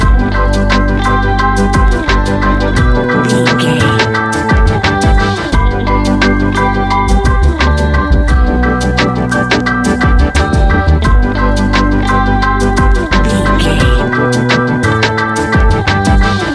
Ionian/Major
chilled
laid back
Lounge
sparse
new age
chilled electronica
ambient
atmospheric
instrumentals